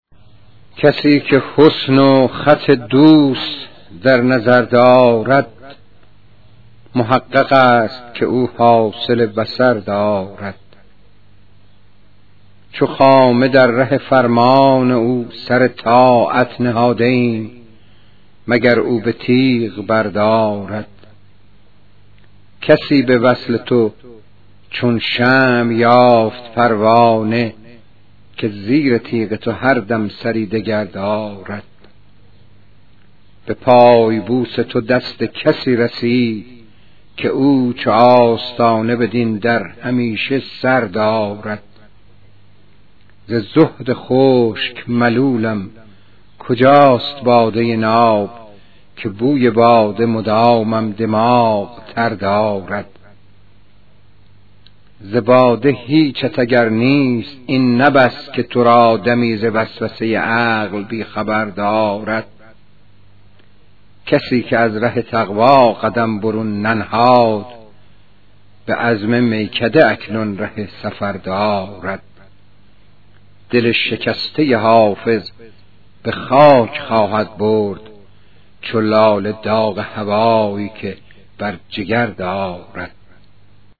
پخش صوتی غزل